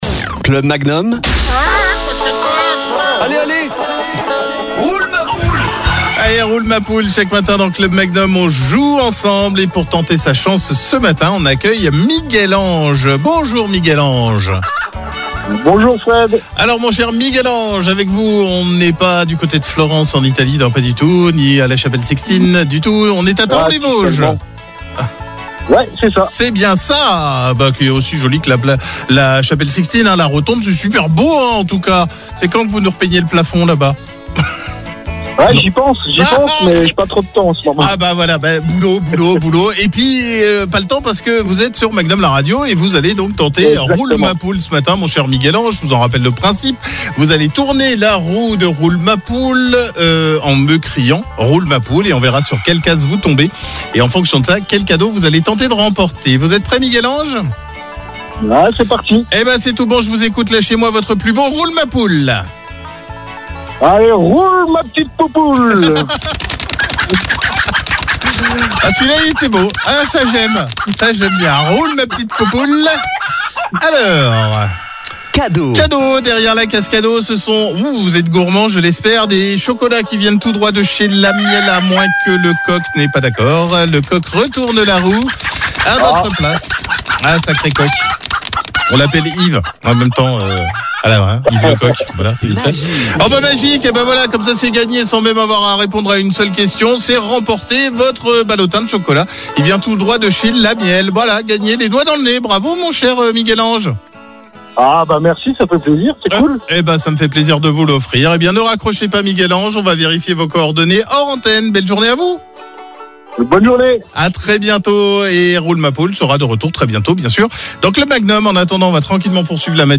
Tournez la roue en criant « Roule ma poule » , plus vous criez fort, plus la roue va tourner.